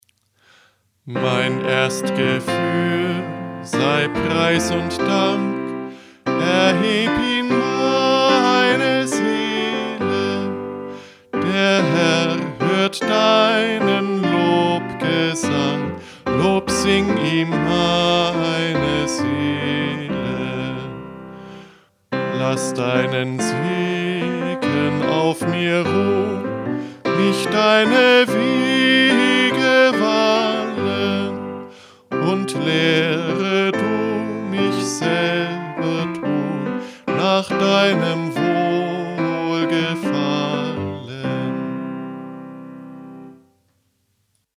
Morgenlied.
Eingesungen: Liedvortrag (